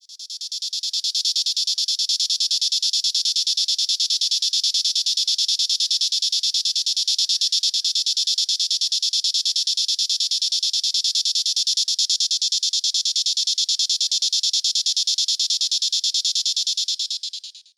SONG
• Calls from cottonwoods etc along rivers.
07.US.AZ.PBL.T03.Neotibicen_cultriformis_filtered.mp3